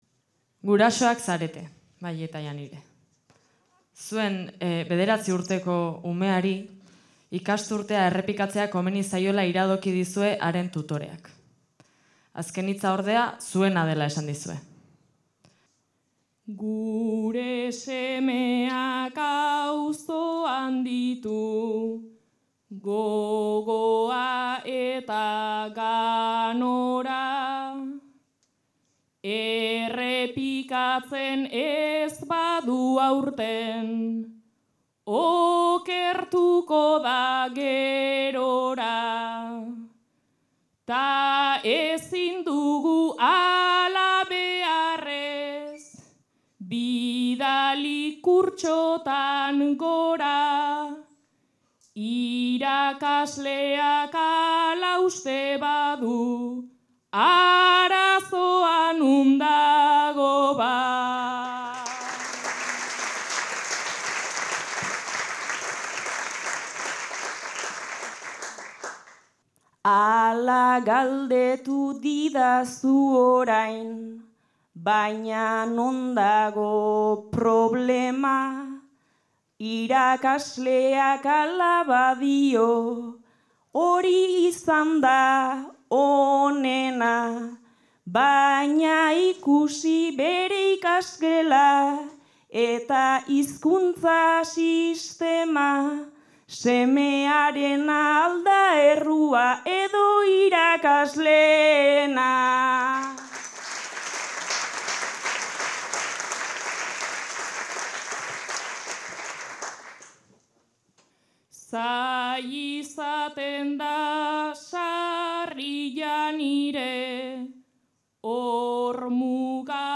Zarautz (Gipuzkoa)
Zortziko handia.